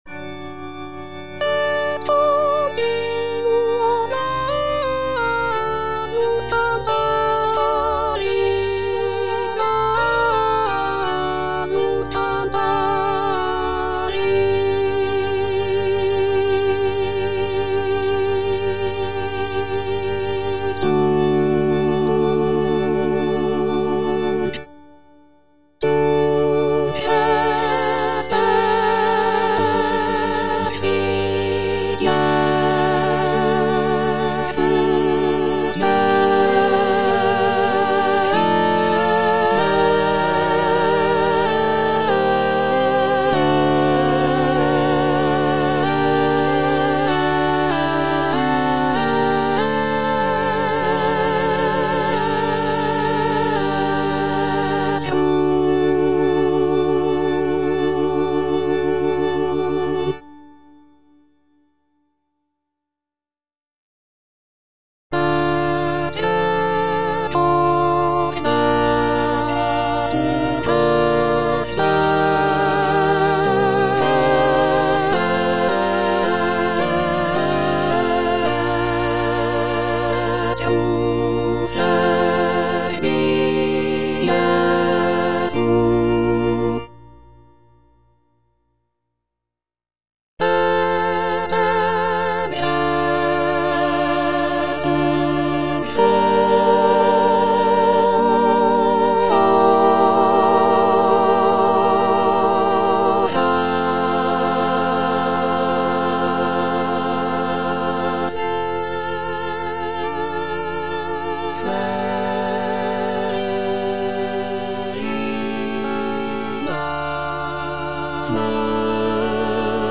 M.A. CHARPENTIER - LE RENIEMENT DE ST. PIERRE Et continuo gallus cantavit (soprano II) (Emphasised voice and other voices) Ads stop:
It is an oratorio based on the biblical story of Saint Peter's denial of Jesus Christ.